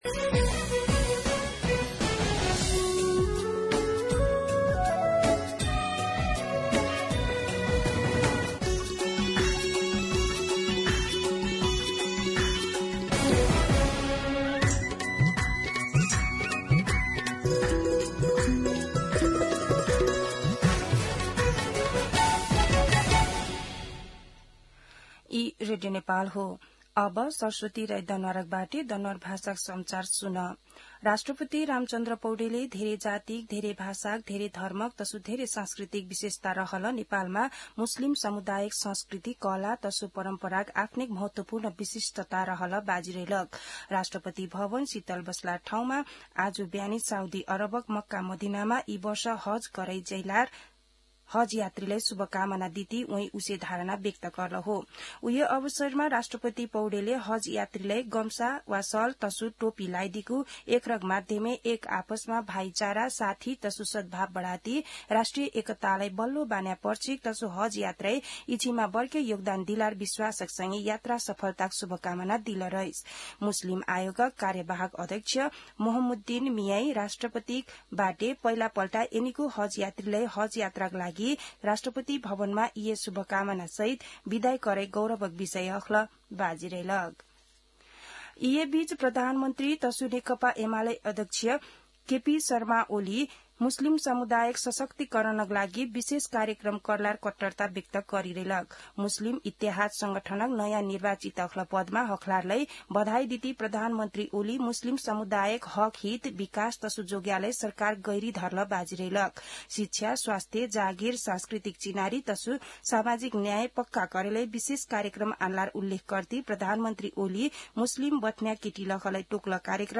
दनुवार भाषामा समाचार : २ जेठ , २०८२